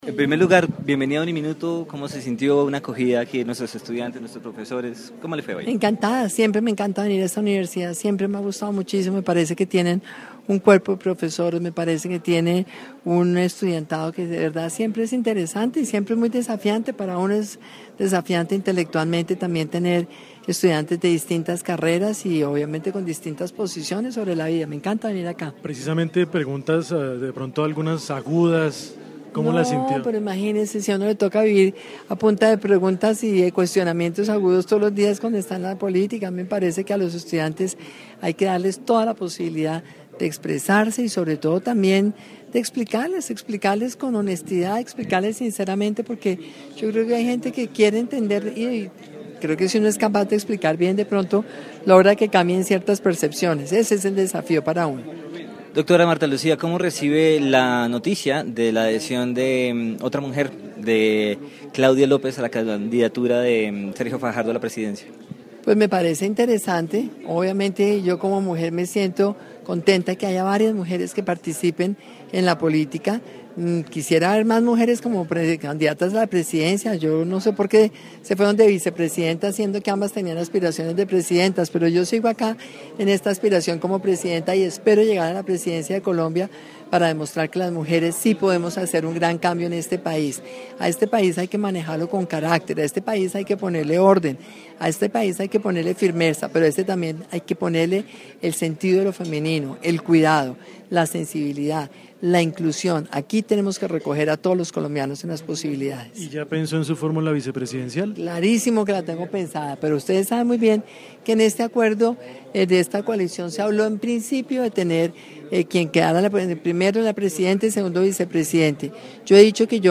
En diálogo con UNIMINUTO Radio estuvo la candidata presidencial Marta Lucia Ramírez quien aseguró que si existiera la posibilidad de no ganar la consulta del 11 de marzo, no se unirá a ninguna de las otras dos campañas con las que compite.
Entrevista-a-Marta-Lucia-Ramirez.mp3